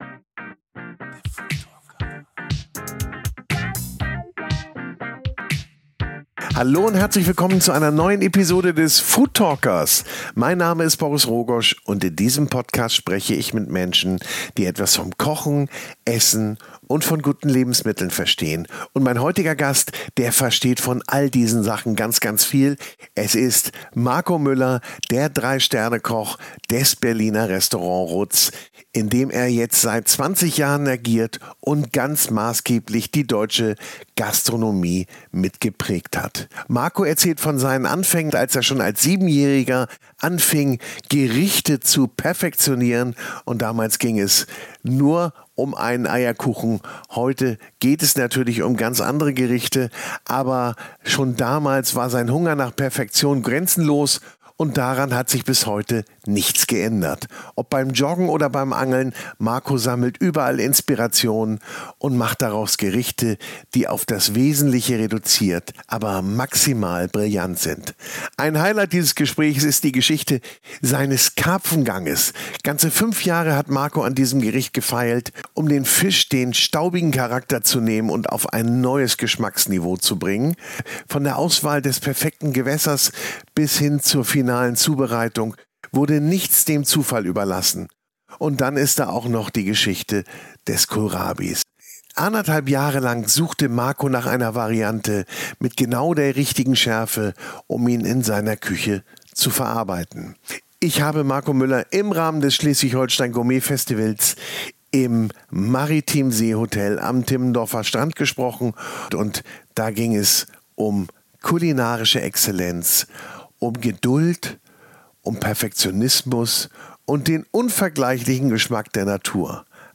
Ich treffe mich mit Sterneköch*innen, Gastronom*innen, Kochbuchautor*innen, Lebensmittelerzeuger*innen, Genuss- und Ernährungsprofis zum Gespräch und versuche zu ergründen, woher ihre Liebe und Passion zum Kochen und zu guten Produkten stammt. Wir sprechen über ihren persönlichen Werdegang, über Esskultur, Leidenschaft und Überzeugung beim Kochen, über gutes Essen und ihre Inspiration.